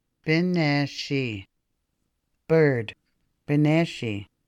Anishinaabemowin Odawa: Bineshiinh    [Bi ne shiinh]